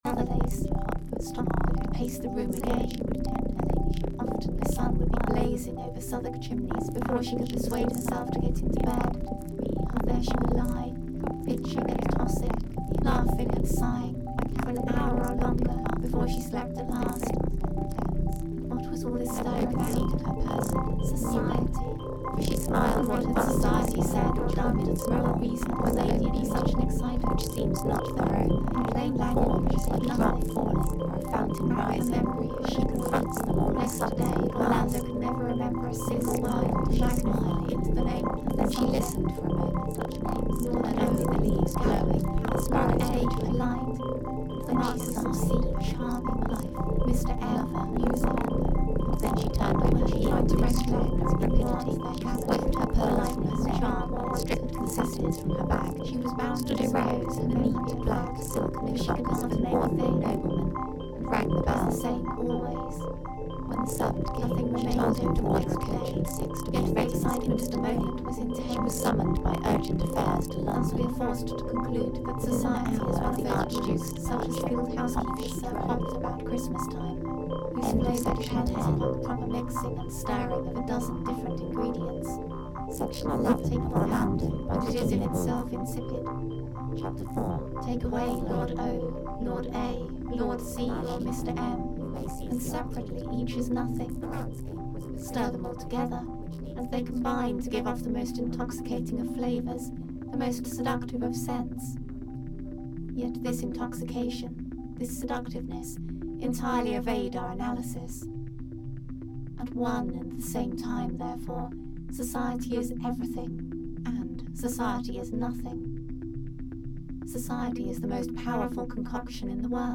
noise music audio tracks
virus-modelled audio material
an anonymous reading